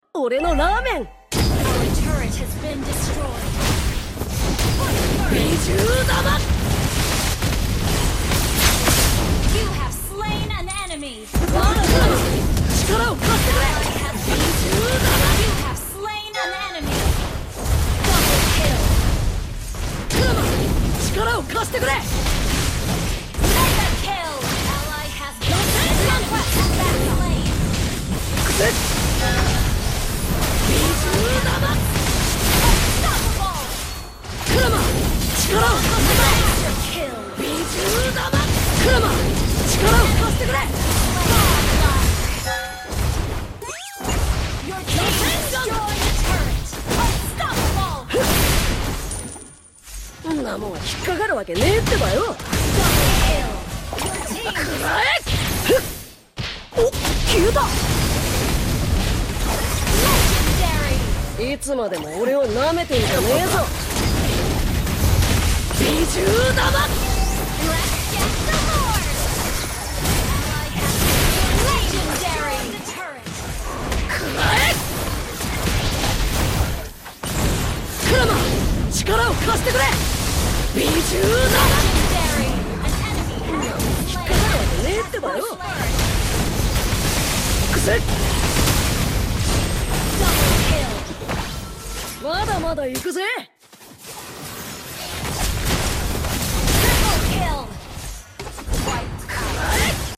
Lukas Naruto Uzumaki Skin Mayhem Gameplay